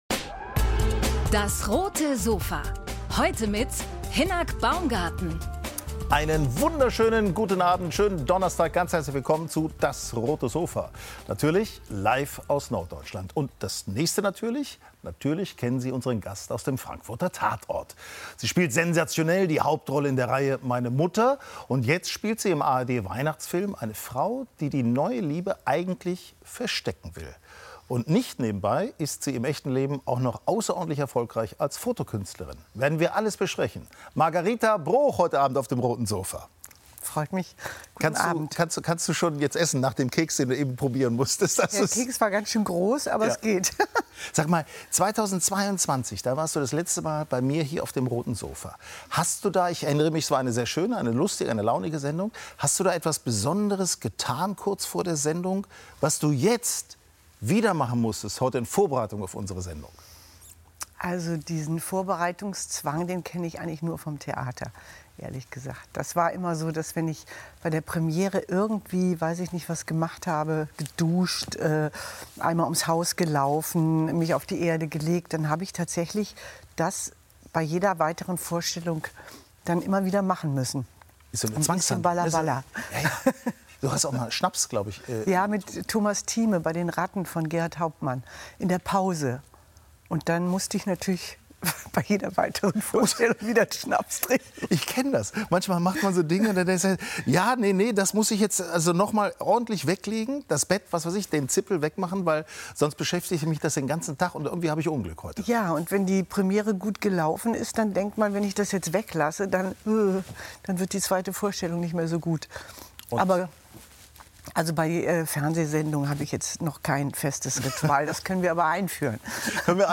Schauspielerin Margarita Broich im Talk auf dem Roten Sofa ~ DAS! - täglich ein Interview Podcast